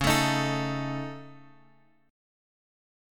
C# Suspended 2nd Sharp 5th